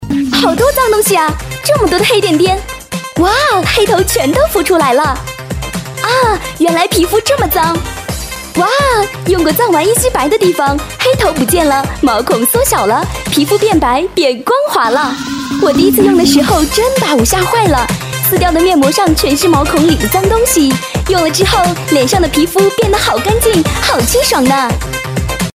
女国133_专题_医院_滨州市医院_温暖-新声库配音网
配音风格： 温暖 大气 自然 甜美 优雅